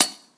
surface_metal3.mp3